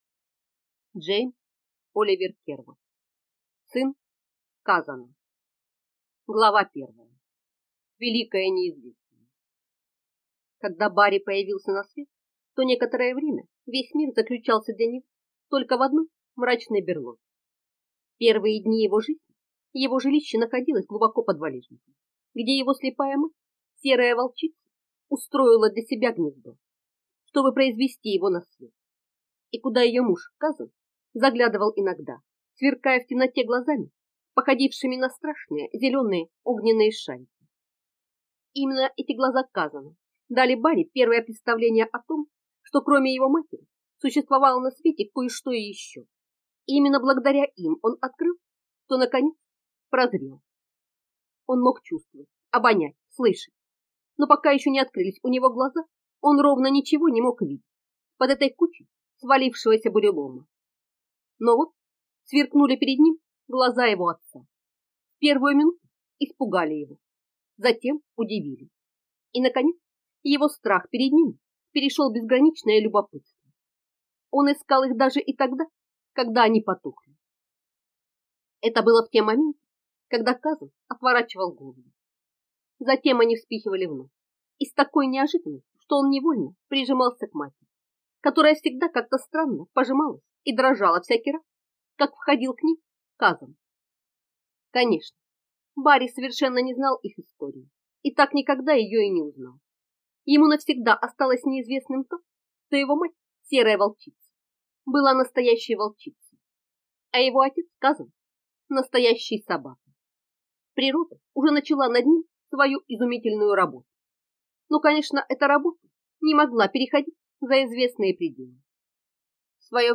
Aудиокнига Сын Казана